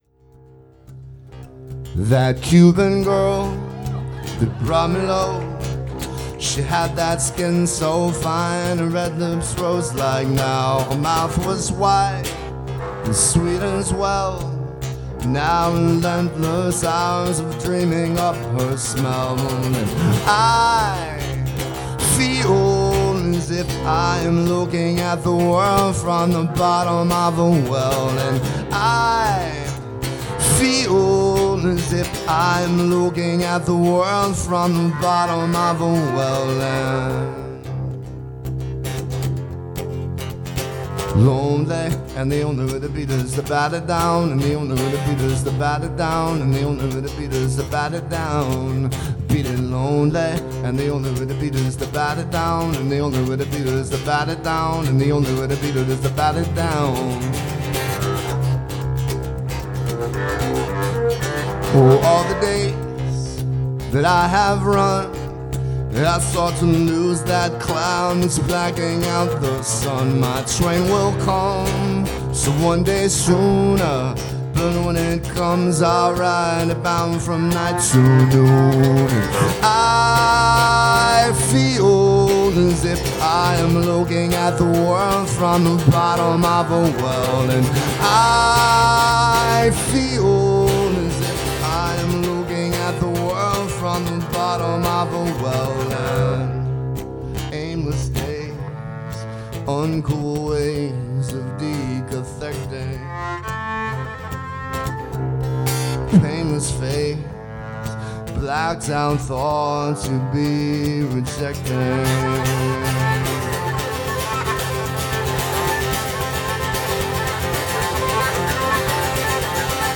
The theme for this weeks Mix.